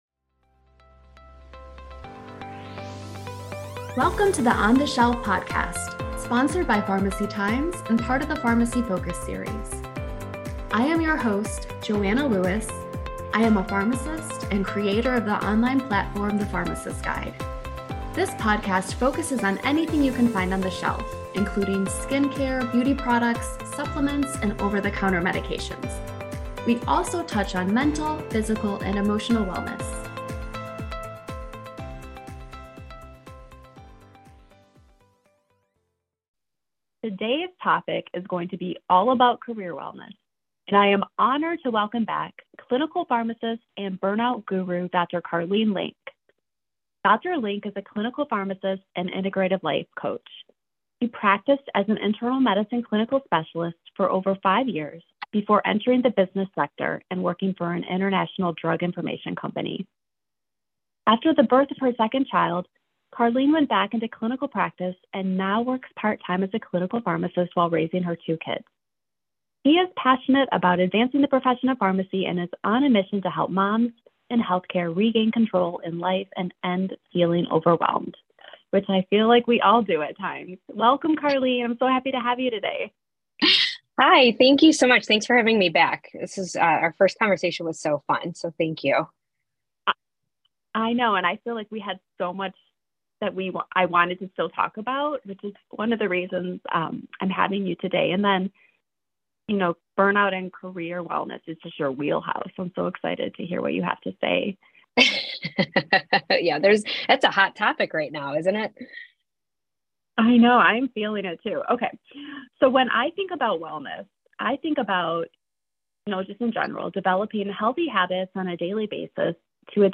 a clinical pharmacist